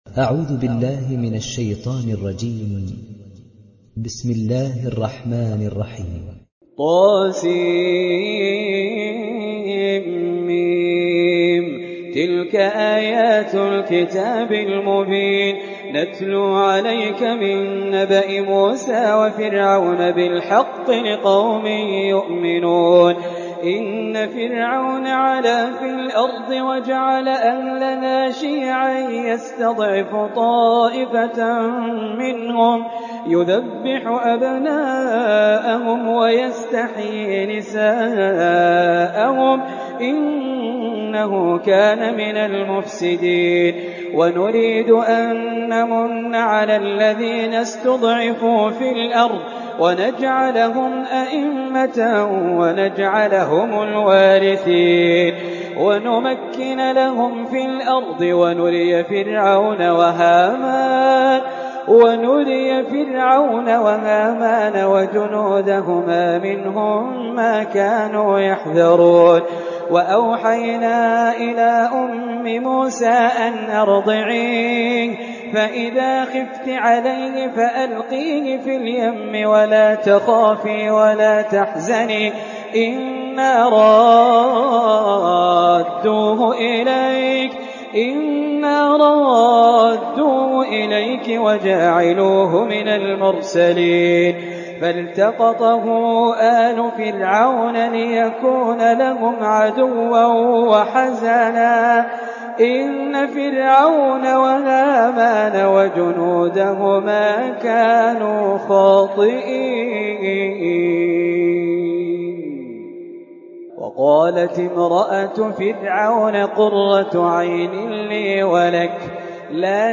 تحميل سورة القصص mp3 بصوت خالد الجليل برواية حفص عن عاصم, تحميل استماع القرآن الكريم على الجوال mp3 كاملا بروابط مباشرة وسريعة